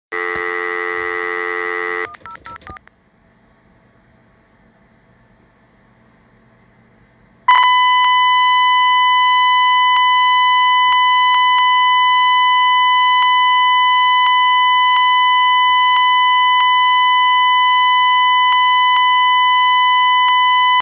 For some reason, using G722 codec caused clicking to be added to the call, but this does not happen with G711.
Clicking sounds "random" to the ear and does not have a steady rhthym.
02-Tone_-_Milliwatt.mp3